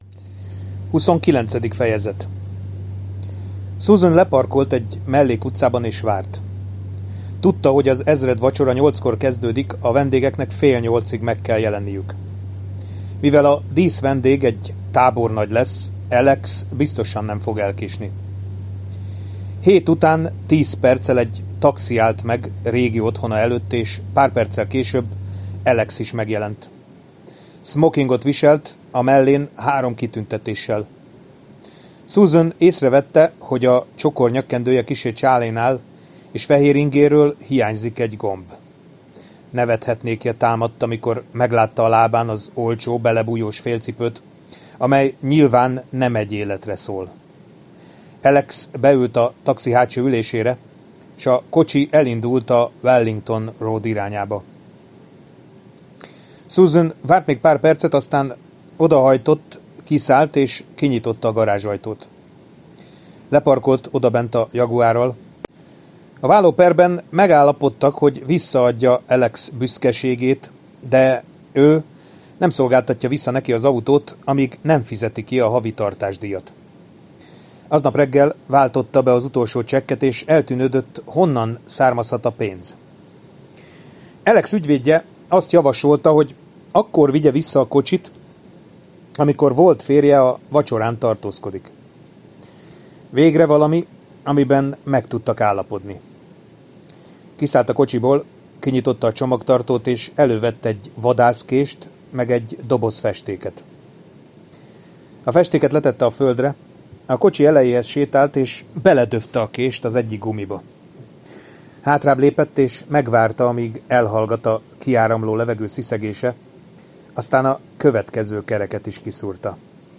Hangoskönyv